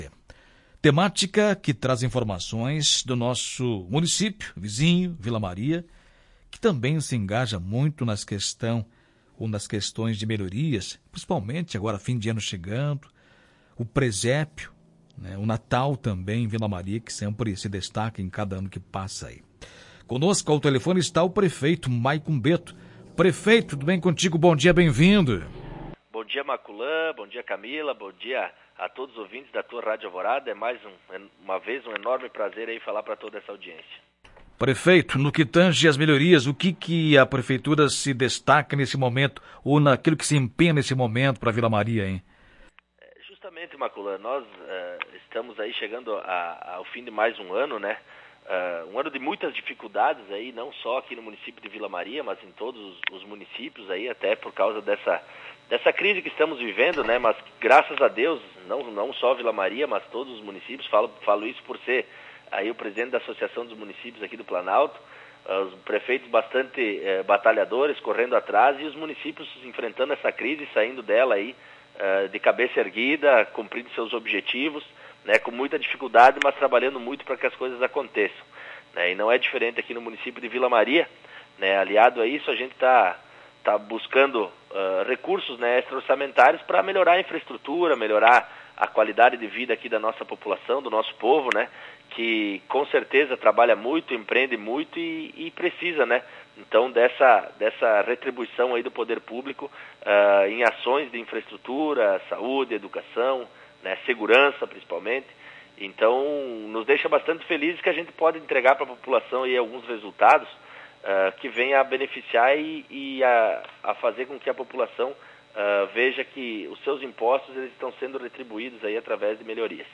Em entrevista à Tua Rádio Alvorada, o prefeito Maico confirma, ainda, que a prefeitura inicia trabalho em turno único nos próximos dias. Ouça a íntegra da entrevista no player de áudio.